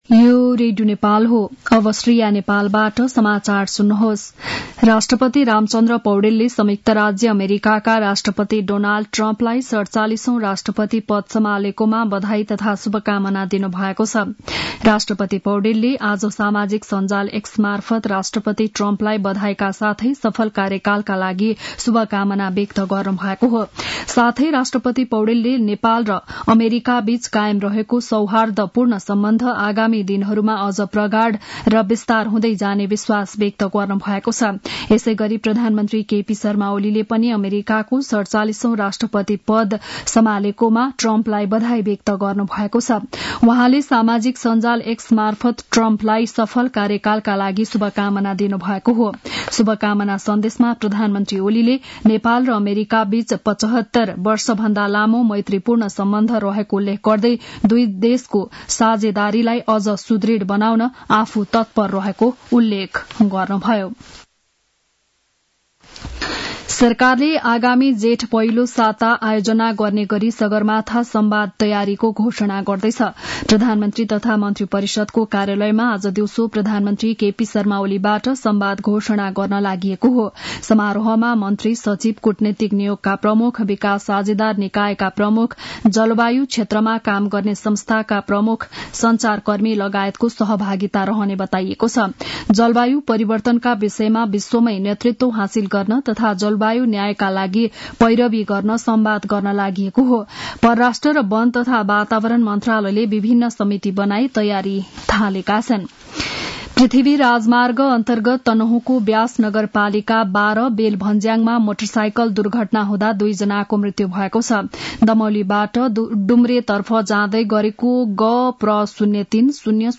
बिहान ११ बजेको नेपाली समाचार : ९ माघ , २०८१
11-am-news-1-10.mp3